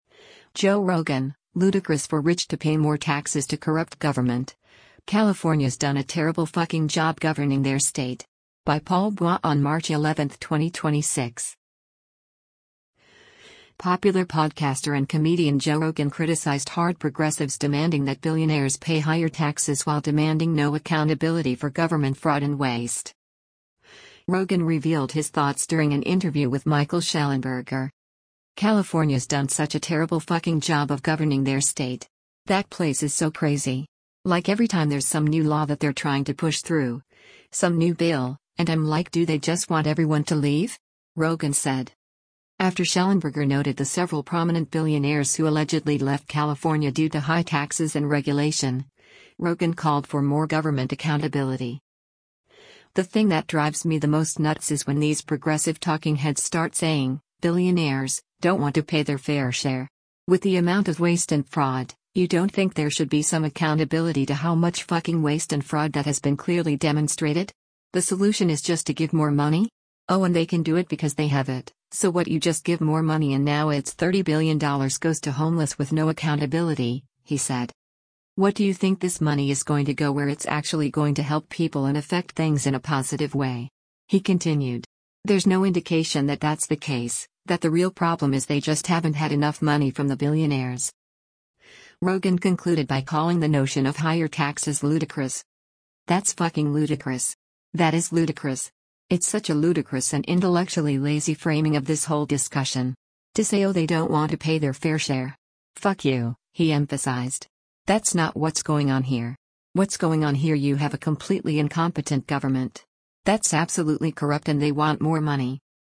Rogan revealed his thoughts during an interview with Michael Shellenberger.